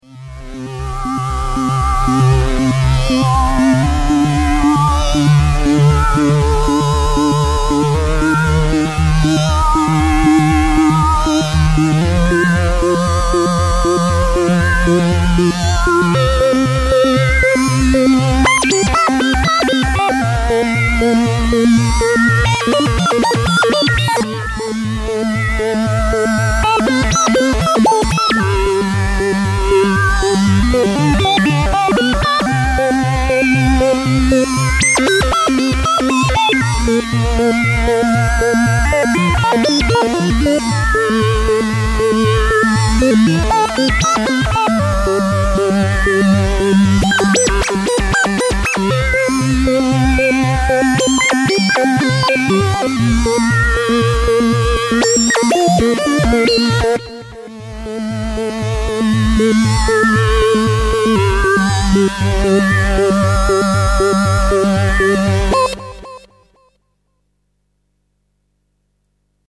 this next soundclip uses an lfo to cycle through the sub-oscillator's octaved waveforms. just to make it clear, there is no arpeggiator or sequencer being used - all live action